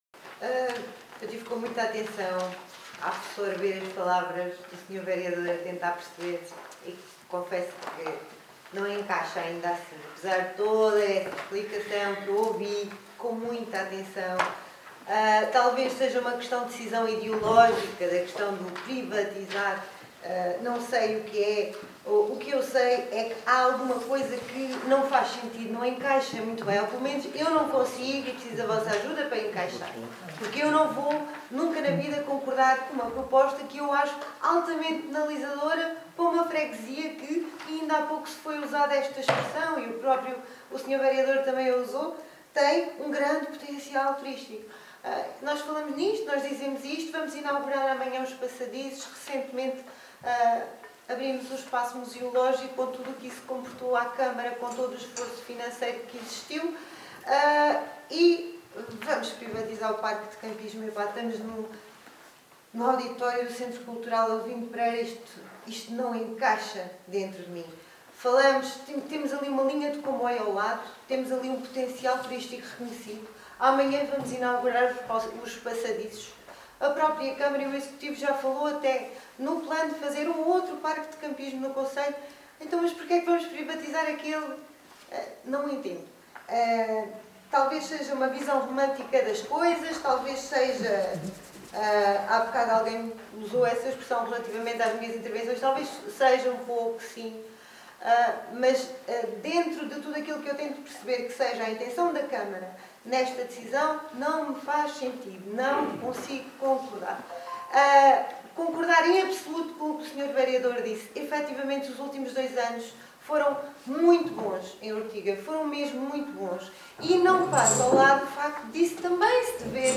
ÁUDIO | Cláudia Cordeiro, deputada do Partido Socialista na Assembleia Municipal